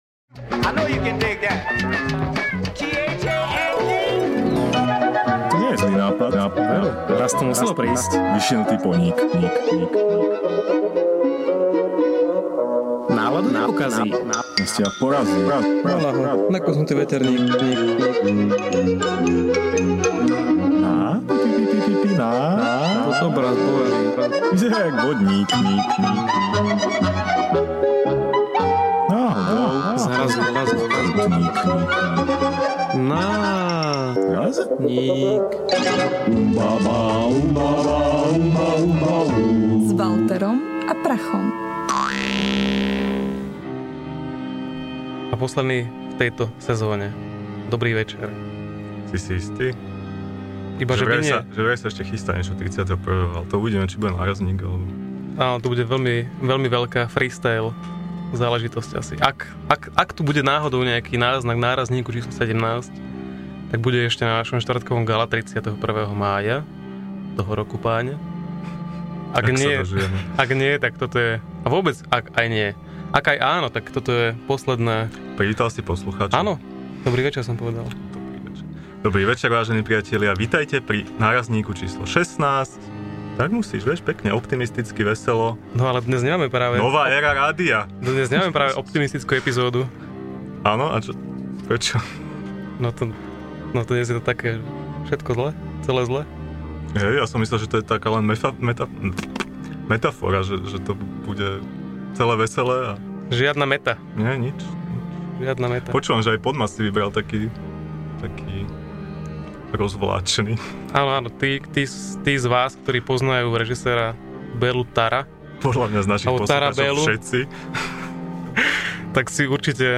NÁRAZNÍK #16 / Celé zle - Podcast NÁRAZNÍK / Ukecaná štvrtková relácia rádia TLIS - Slovenské podcasty